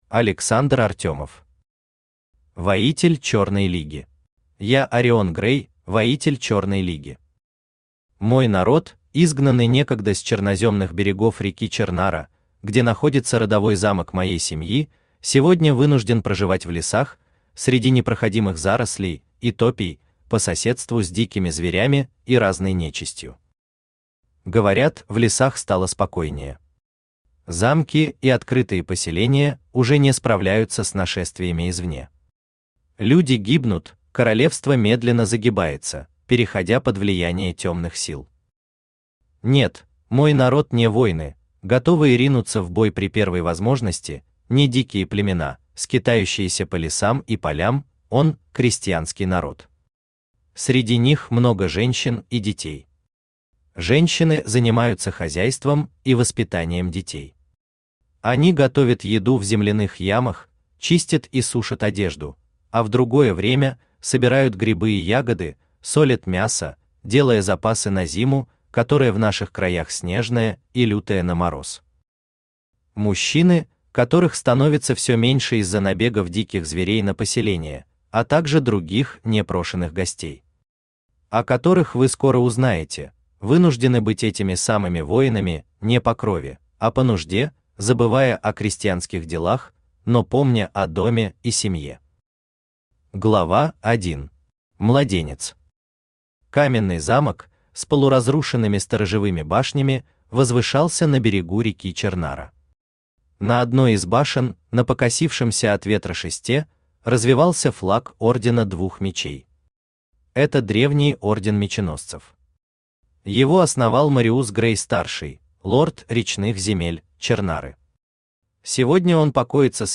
Аудиокнига Воитель Черной Лиги | Библиотека аудиокниг
Aудиокнига Воитель Черной Лиги Автор Александр Артемов Читает аудиокнигу Авточтец ЛитРес.